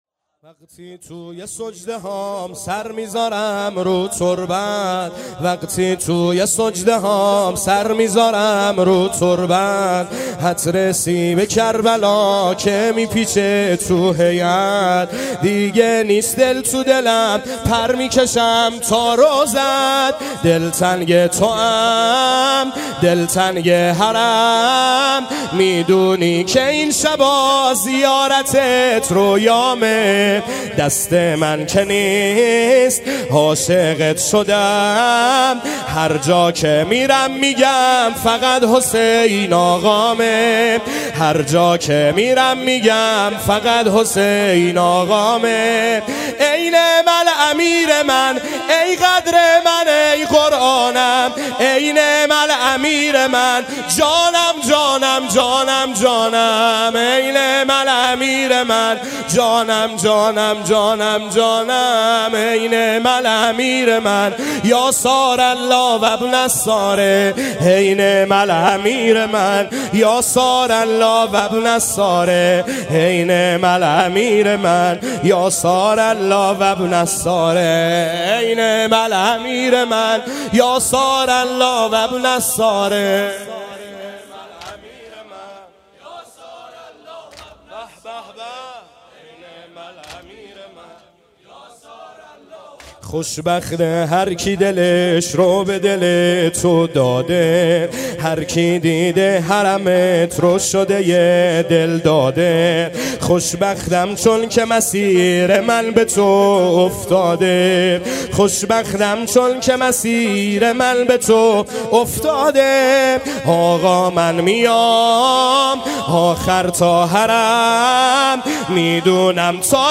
واحد | ای نعم الامیر من یا ثارالله
گزارش صوتی شب چهارم محرم 98 | هیأت محبان حضرت زهرا سلام الله علیها زاهدان